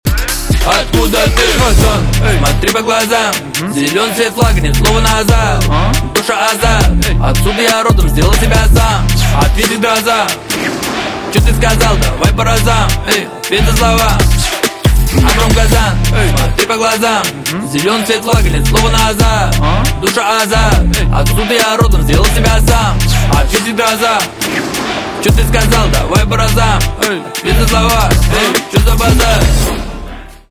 русский рэп
басы